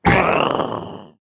Minecraft Version Minecraft Version latest Latest Release | Latest Snapshot latest / assets / minecraft / sounds / entity / shulker / death1.ogg Compare With Compare With Latest Release | Latest Snapshot
death1.ogg